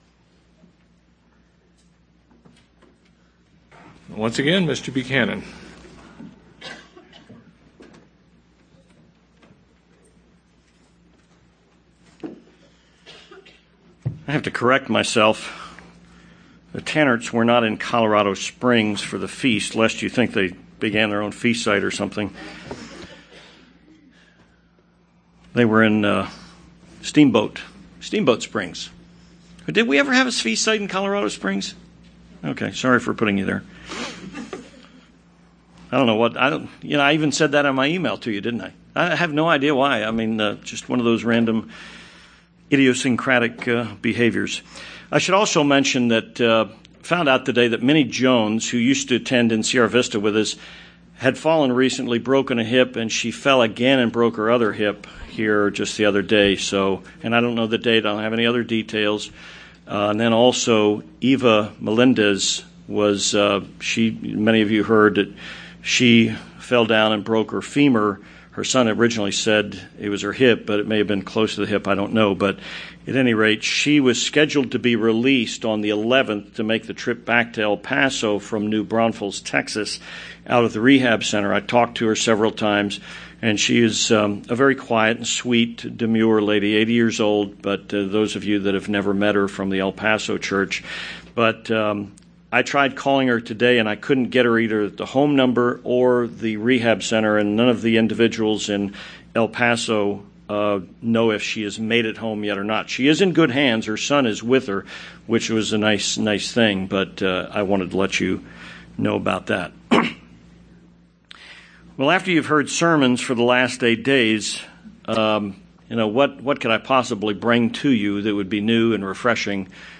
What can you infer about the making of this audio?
What model of rulership will be followed in the Kingdom of God? This sermon builds on the lessons that have been presented at the 2012 Feast of Tabernacles.